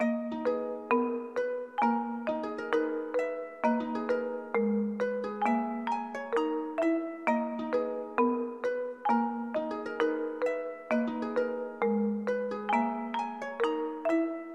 拉芬德鼓
描述：128bpm，长度为16巴。在Samplitude Beatbox中创建。我的第一个鼓循环（用一些低音和合成器加强它）
Tag: 贝司 鼓环 节拍 舞蹈 128bpm 鼓正贝司